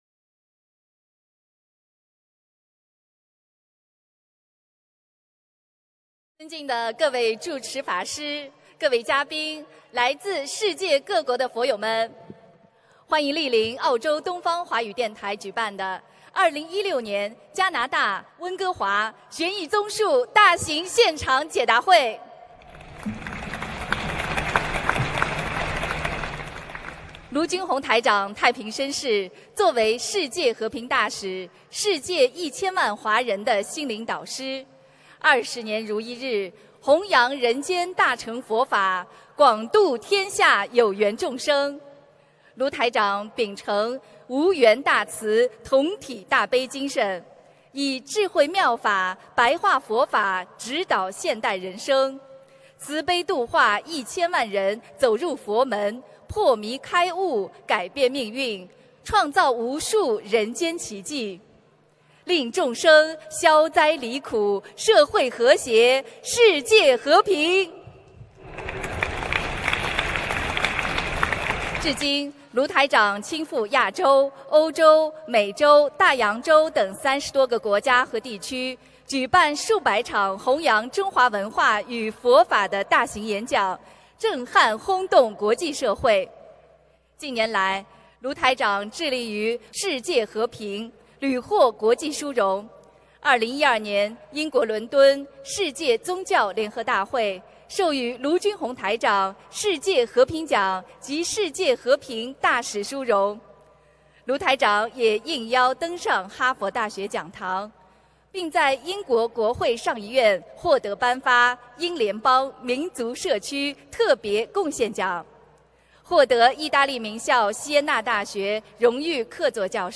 2016年9月3日加拿大温哥华解答会开示（视音文图） - 2016年 - 心如菩提 - Powered by Discuz!